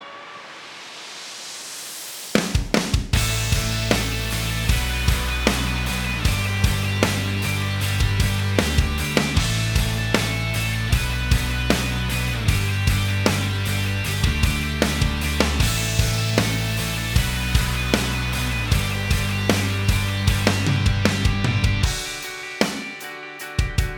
Minus All Guitars Pop (2010s) 3:39 Buy £1.50